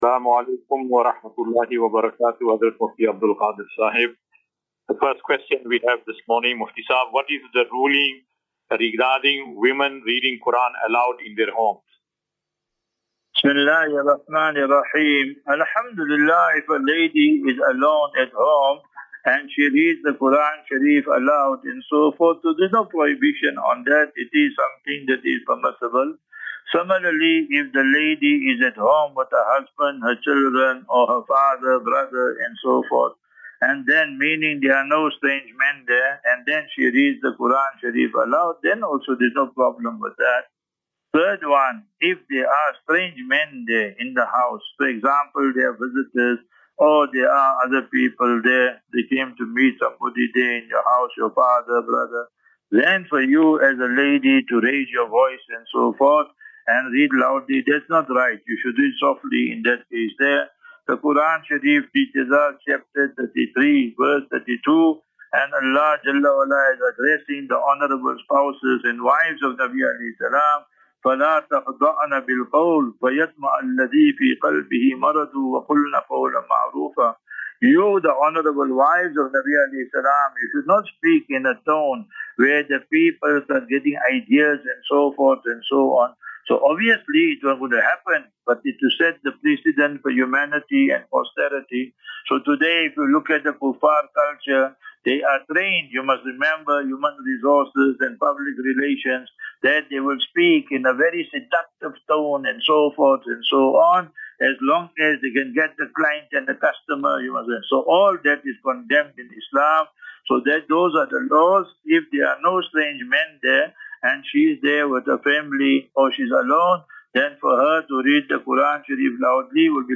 View Promo Continue Install As Safinatu Ilal Jannah Naseeha and Q and A 28 Mar 28 March 2025.